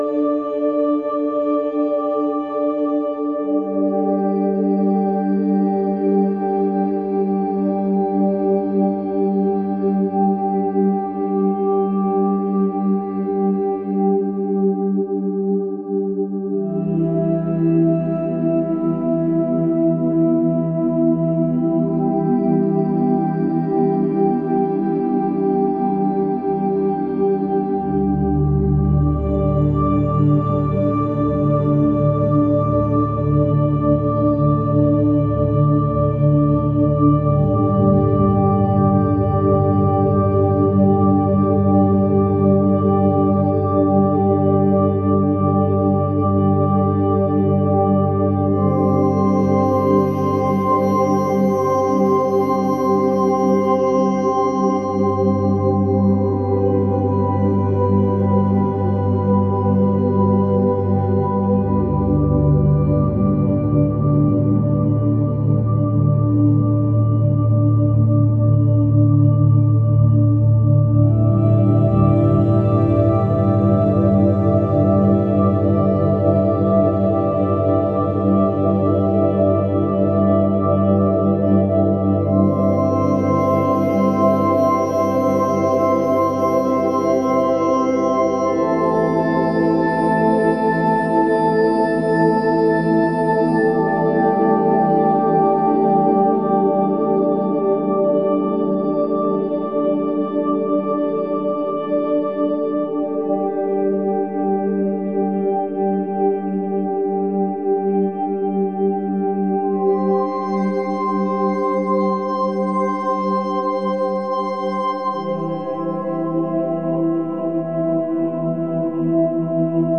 forest.ogg